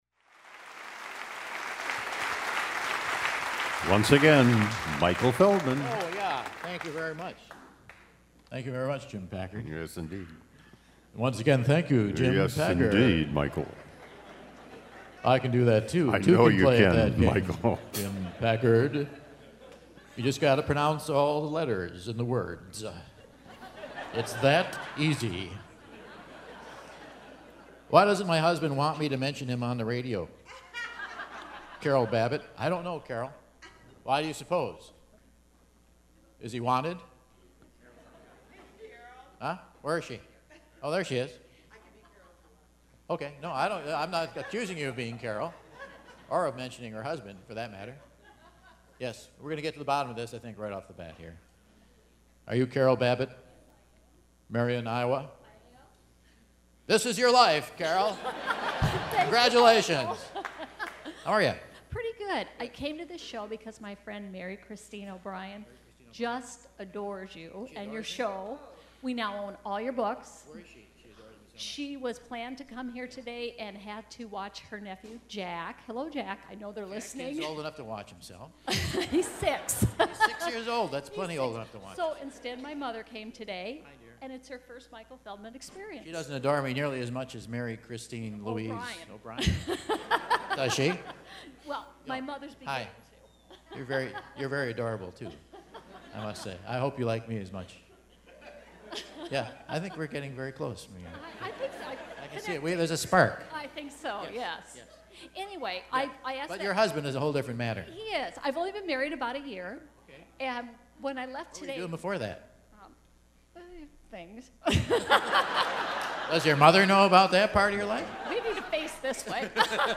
Quiz B - September 15, 2012 in Dubuque, IA